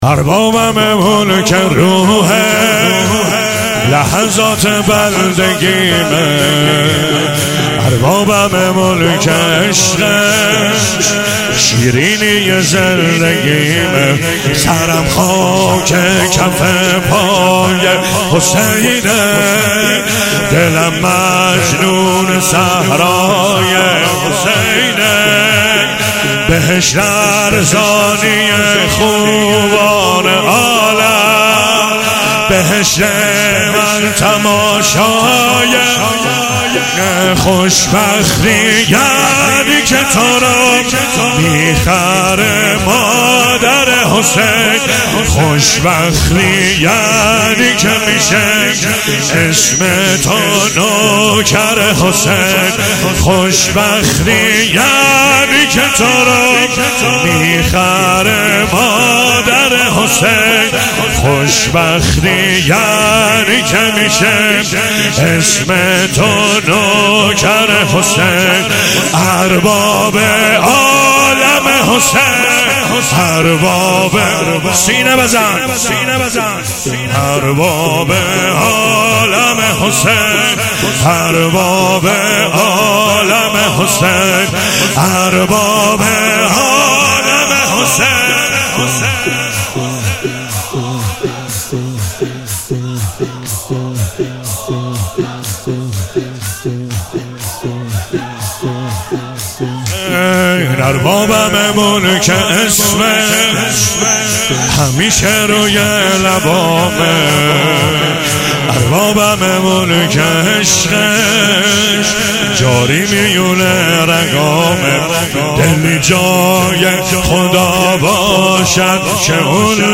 عنوان مراسم شب اول ماه مبارک رمضان
شور